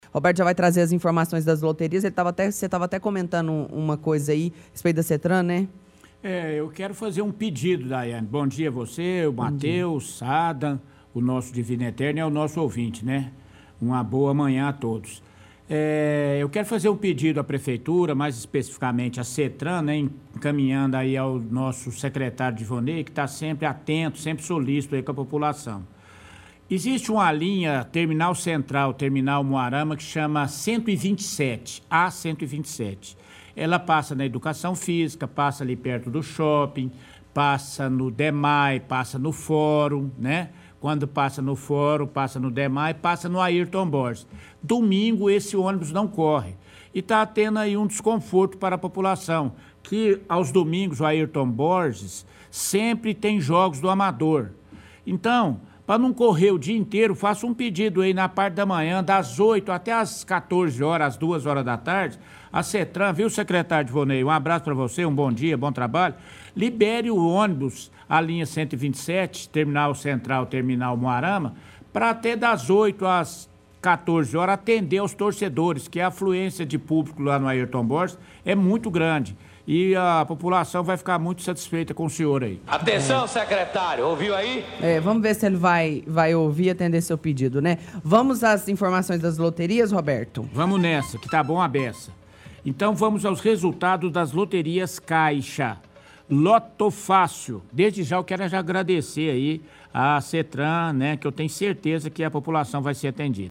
– Repórter da rádio reclama da linha de ônibus A127 que não corre nos domingos, o que prejudica os cidadãos que querem assistir a jogos do campeonato amador.